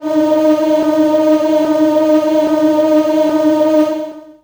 55be-syn12-d#3.aif